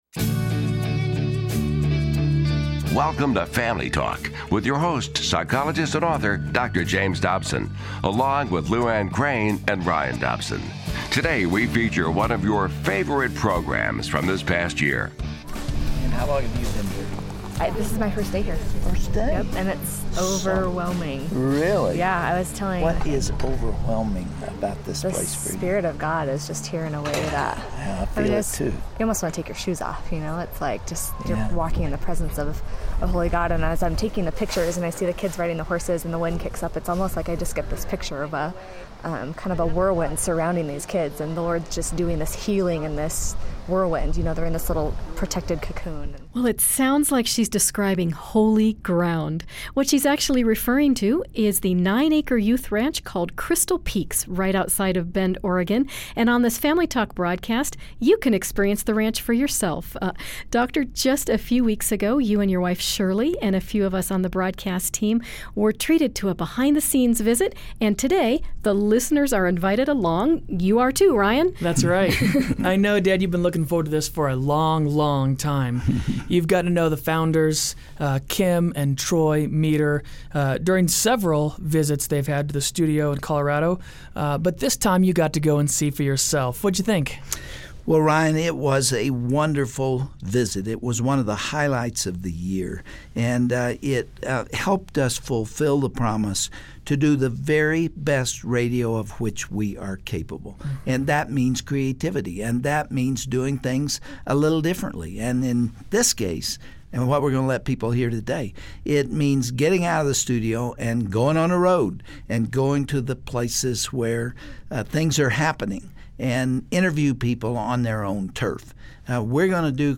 One of our fondest memories of 2010 was a first-hand look at the Oregon horse ranch that provides hope and healing to kids and adults alike. Join Family Talk's up-close-and-personal tour of Crystal Peaks Youth Ranch and meet some amazing new friends along the way.